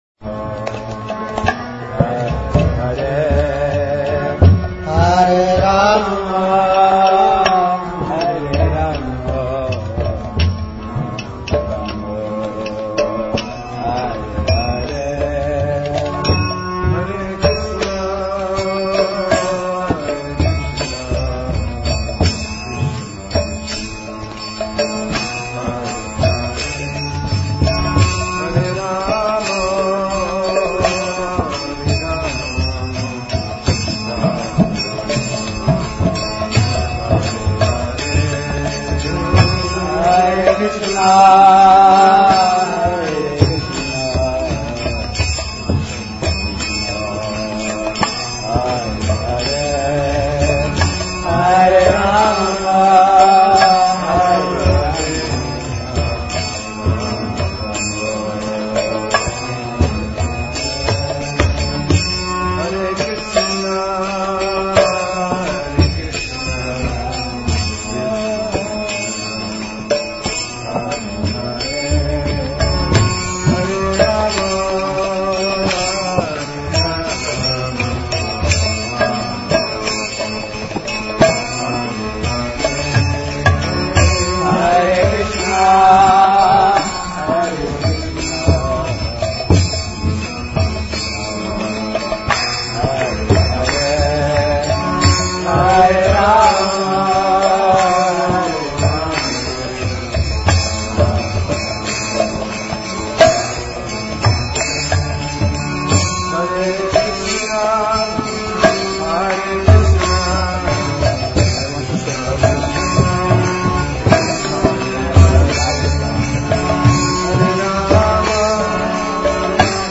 Kirtana